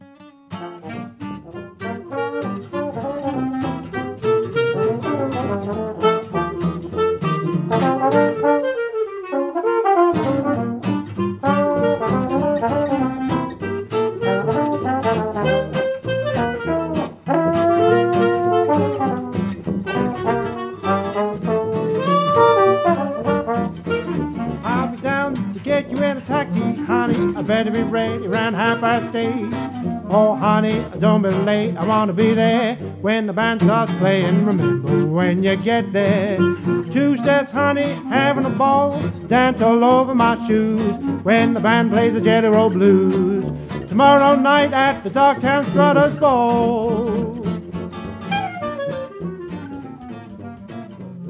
• Versatile, genre-hopping jazz ensemble
• Traditional jazz but with a modern twist
• Inspired by '20s/'30s New Orleans music